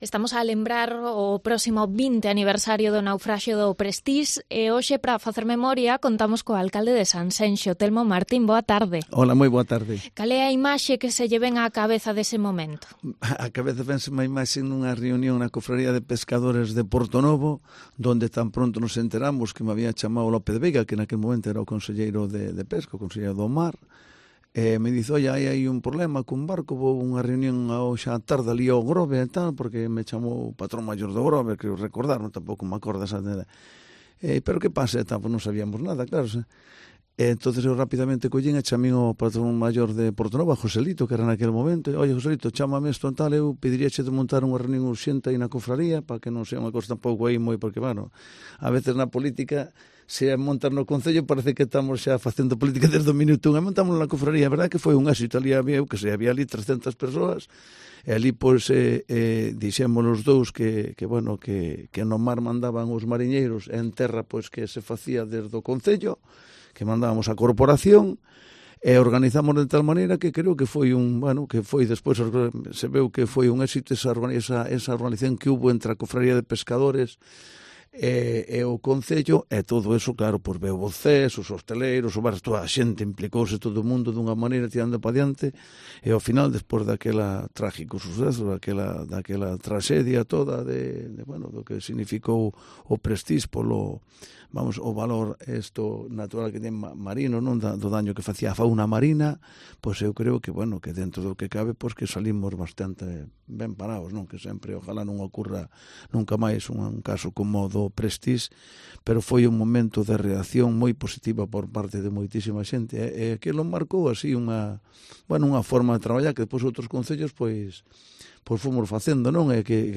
Entrevista al alcalde de Sanxenxo, Telmo Martín, rememorando el naufragio del Prestige 20 años después